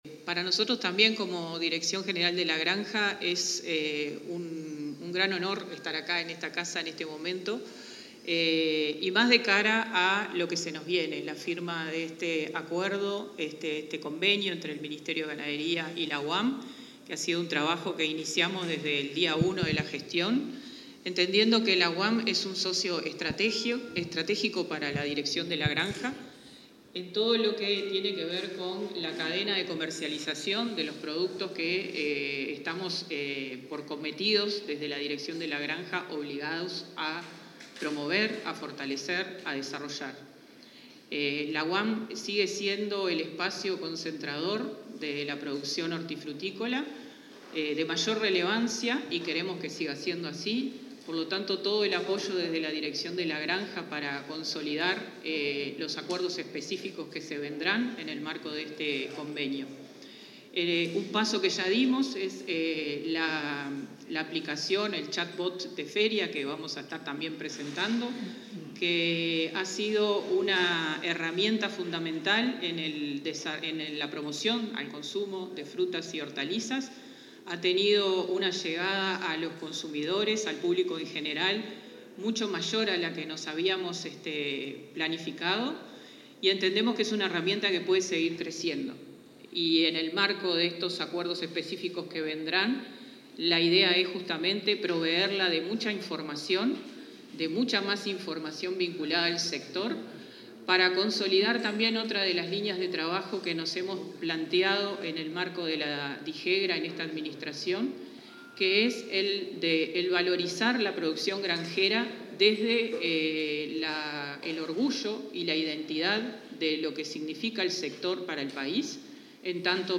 Palabras de autoridades en firma de acuerdo entre MGAP y UAM
Durante la firma de un acuerdo entre el Ministerio de Ganadería, Agricultura y Pesca (MGAP) y la Unidad Agroalimentaria Metropolitana (UAM) para una cooperación recíproca en materia de producción, comercialización, innovación y alimentación sostenible, se expresaron el subsecretario del MGAP, Matías Carámbula, y la directora nacional de la Granja, Laura González.